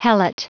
Prononciation du mot helot en anglais (fichier audio)
Prononciation du mot : helot